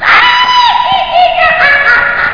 GHOST.mp3